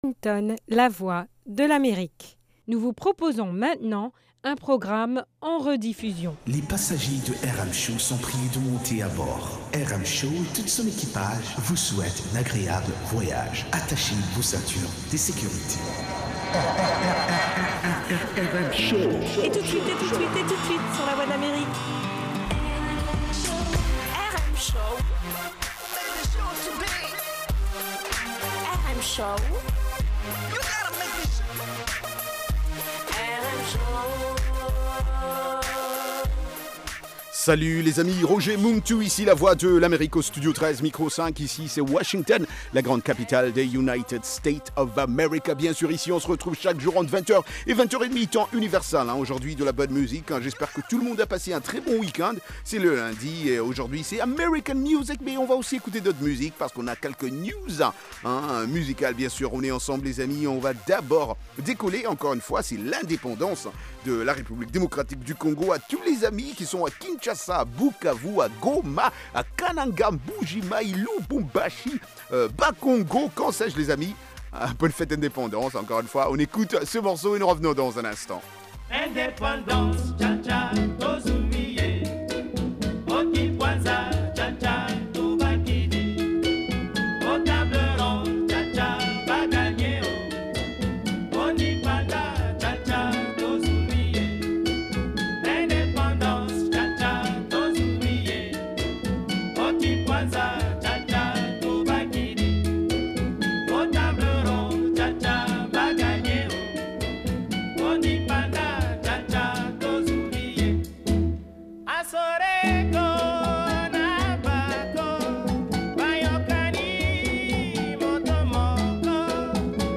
Cette émission est interactive par téléphone.